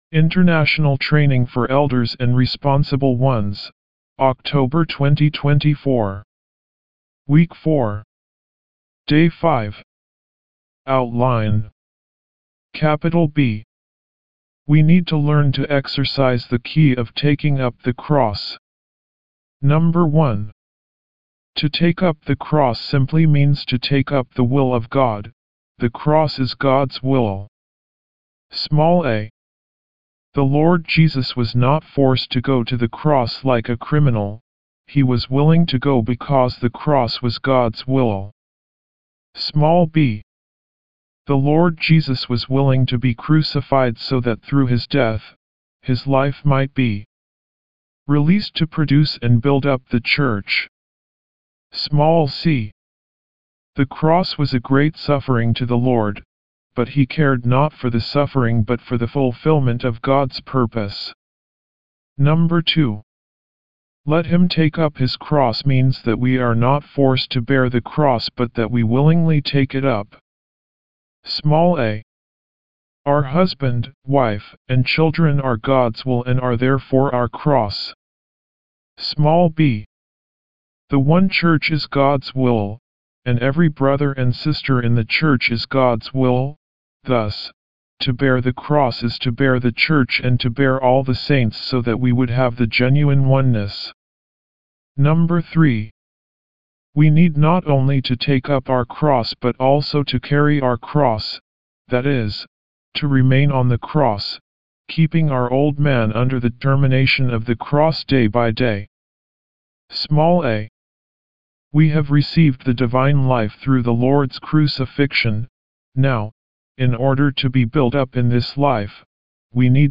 W4  Outline Recite
D5 English Rcite：